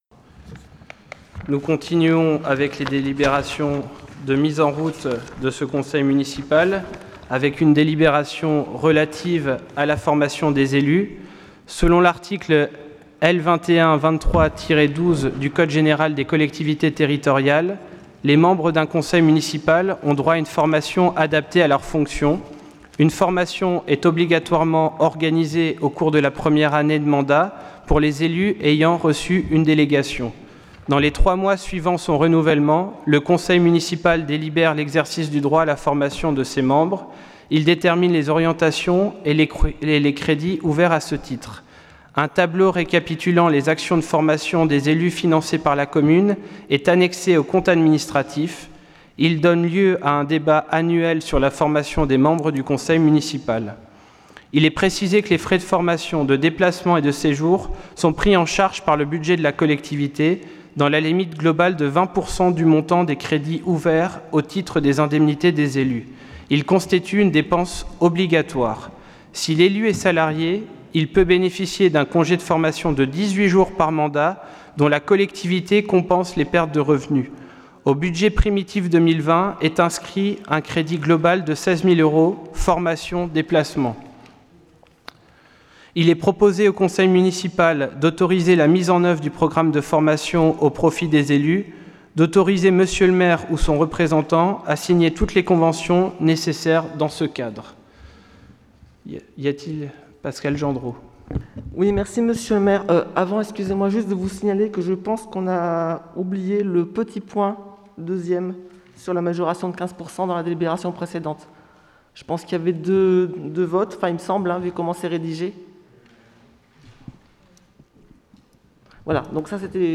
Point 4 : Formation des élus Conseil Municipal du 04 juillet 2020